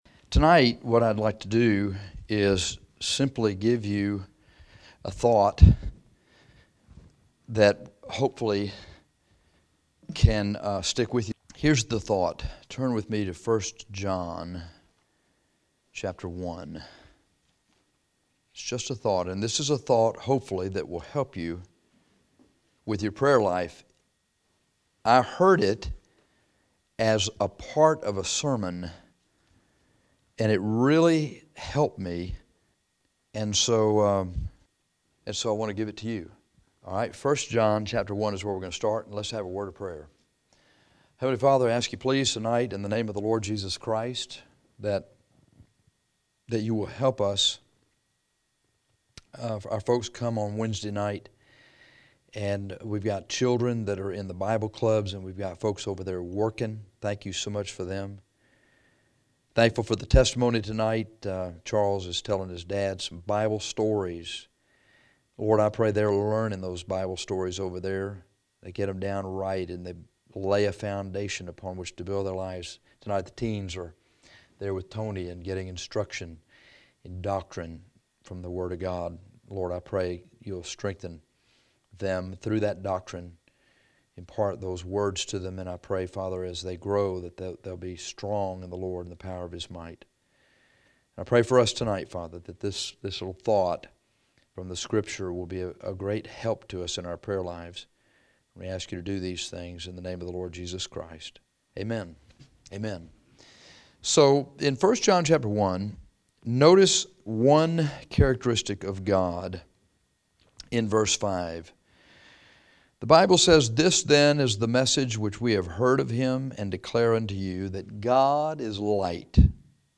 This sermon is simply a thought that might help you in your prayer life to draw closer to the Lord.